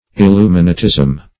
Meaning of illuminatism. illuminatism synonyms, pronunciation, spelling and more from Free Dictionary.
Illuminatism \Il*lu"mi*na*tism\, n.